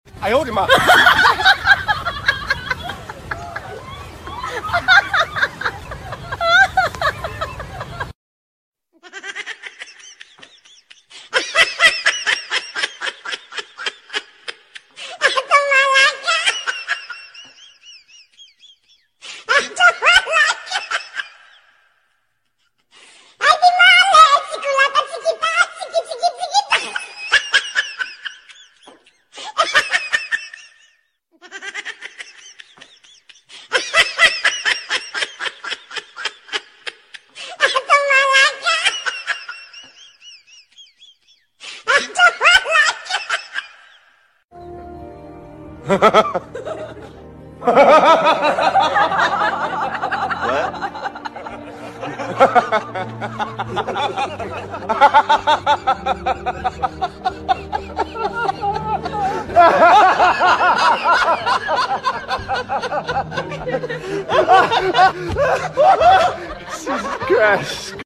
Laughter sound for video editing. sound effects free download
Mp3 Sound Effect Laughter sound for video editing.